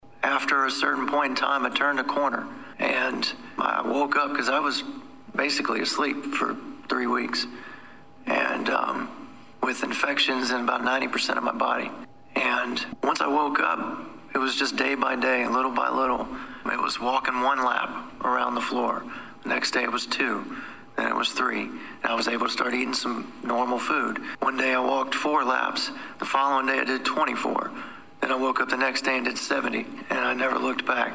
Just under three months after he was attacked and set on fire Danville City Councilman Lee Vogler attended his first city council meeting since the attack tonight.
Vogler also spoke about his recovery so far.